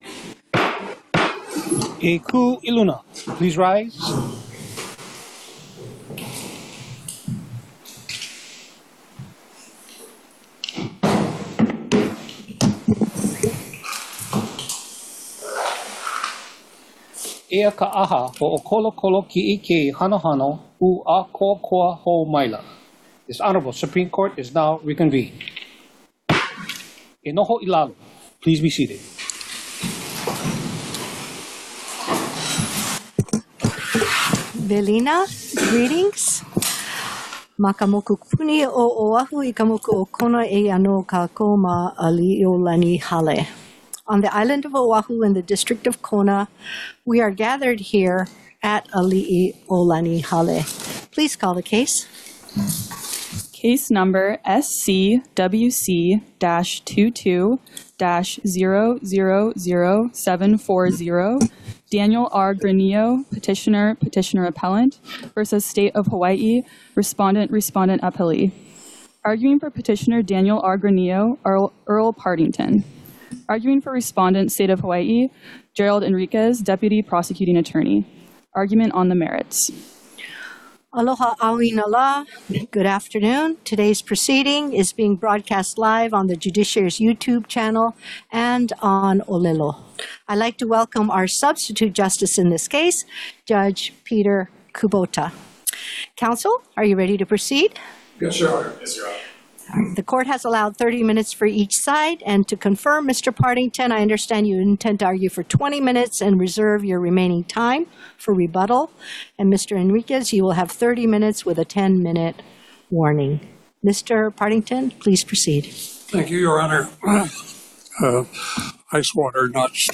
The above-captioned case has been set for oral argument on the merits at: